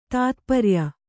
♪ tātparya